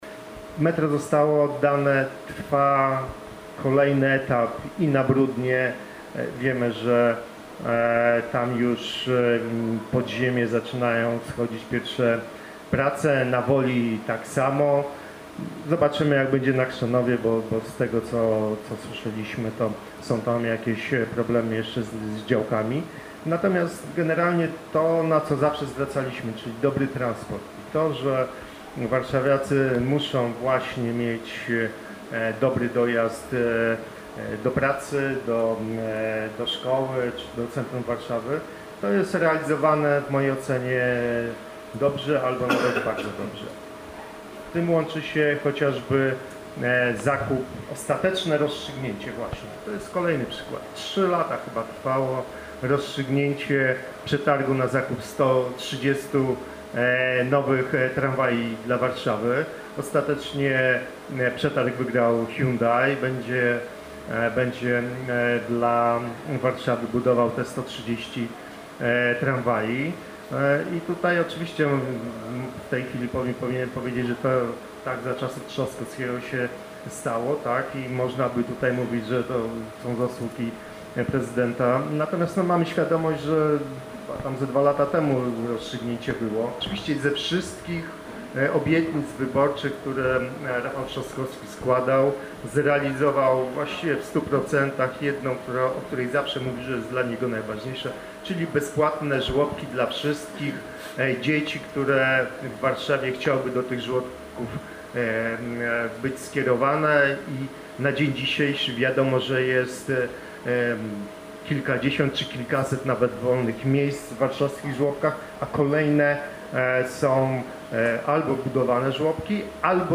Z tej okazji zorganizowano debatę podsumowującą rok rządów prezydenta stolicy Rafała Trzaskowskiego.
Fragment wypowiedzi radnego Pawła Lecha: